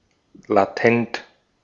Ääntäminen
IPA : /ˈleɪ.tənt/